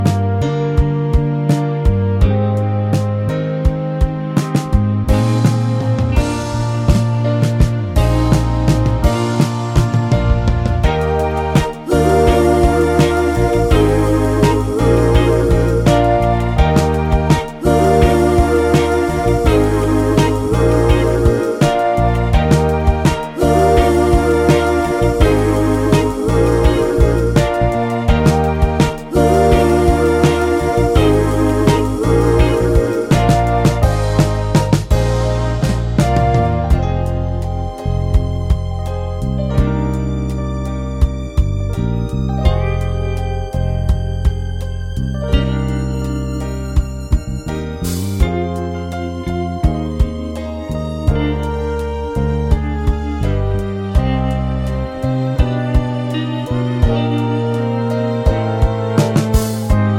no Backing Vocals Duets 3:33 Buy £1.50